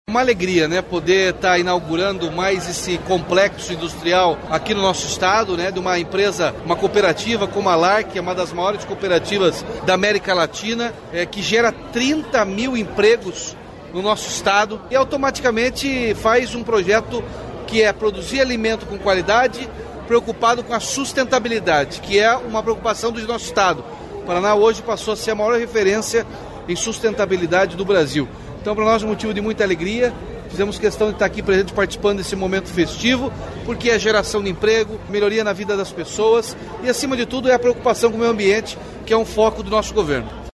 Sonora do governador Ratinho Junior sobre a visita na sede da Lar Cooperativa Agroindustrial em Matelândia